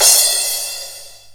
CRASH01.WAV